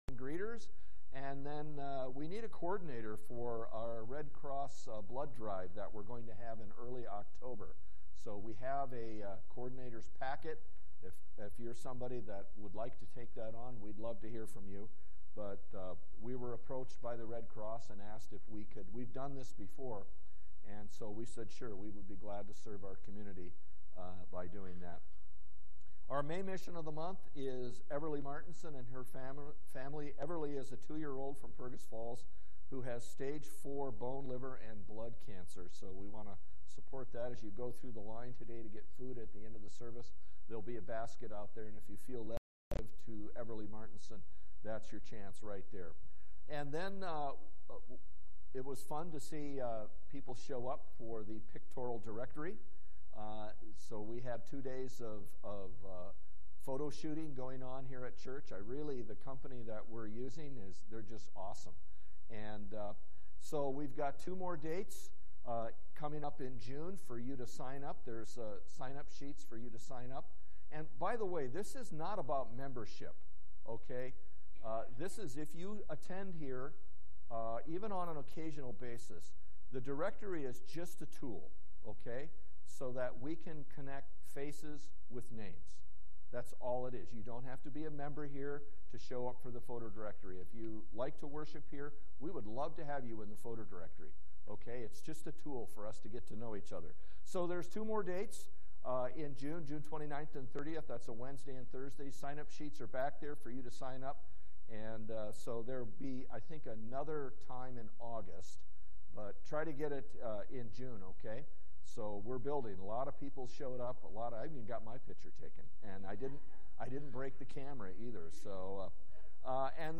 From Series: "Sunday Worship"
Sunday-Service-5-29-22.mp3